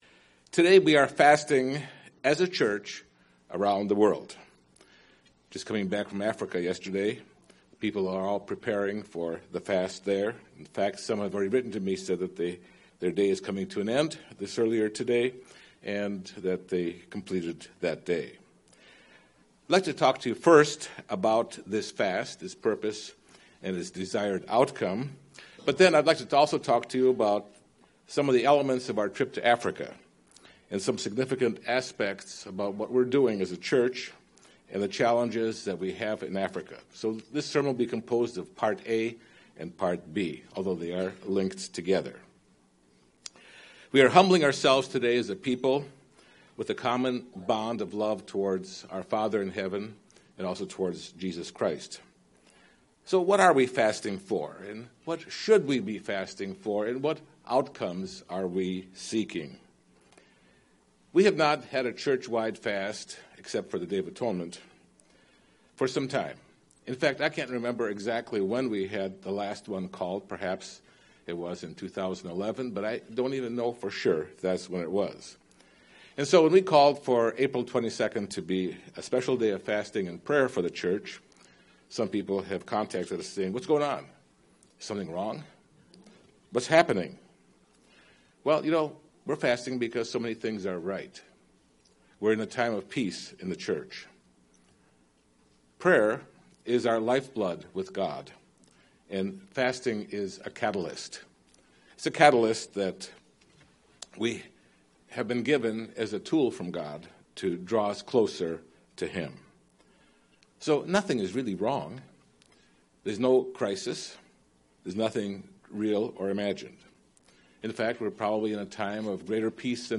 We're fasting as a church together on the same day because we have a common purpose in doing God's work together as we search for answers in how to do it. Also, listen about great strides in work in South Africa, Malawi and Zambia from our recent trip. UCG Sermon Africa Transcript This transcript was generated by AI and may contain errors.